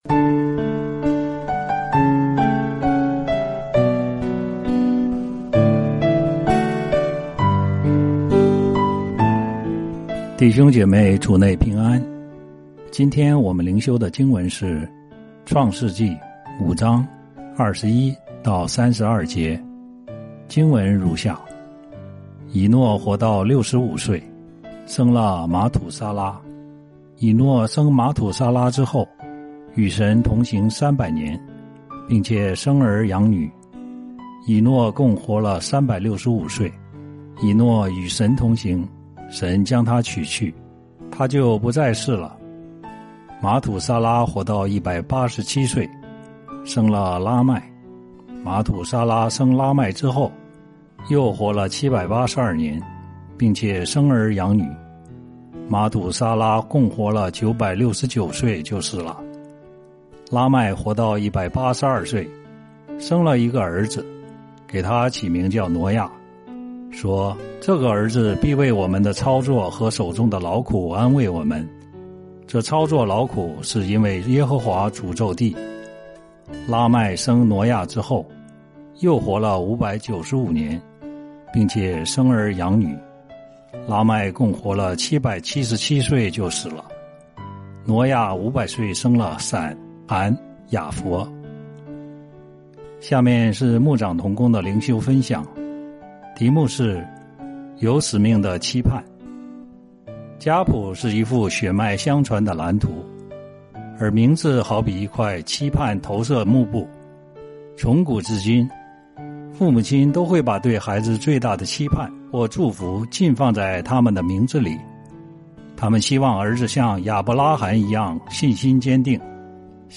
這是把通讀整卷創世記和每天靈修結合起來的一個計劃。每天閱讀一段經文，聆聽牧者的靈修分享，您自己也思考和默想，神藉著今天的經文對我說什麼，並且用禱告來回應當天的經文和信息。